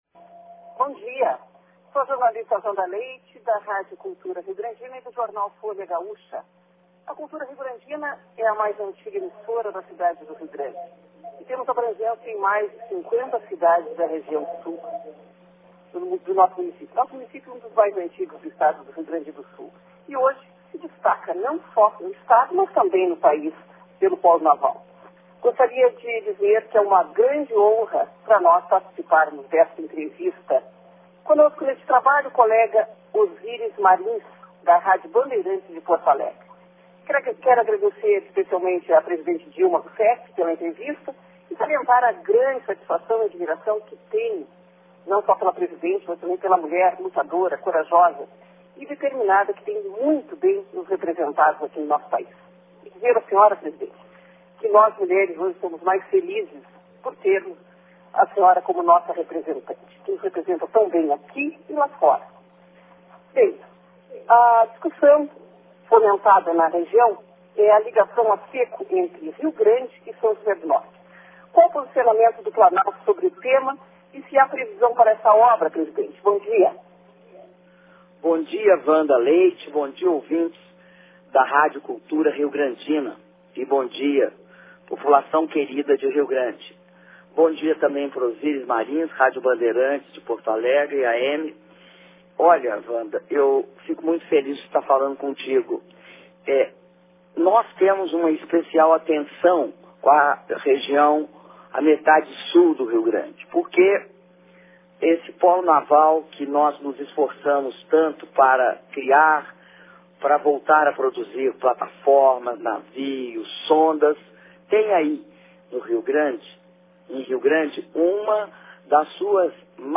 Entrevista coletiva concedida pela Presidenta da República, Dilma Rousseff, para a Rádio Cultura Riograndina AM e Rádio Bandeirantes AM de Porto Alegre/RS - Brasília/DF
Palácio da Alvorada - DF, 17 de setembro de 2013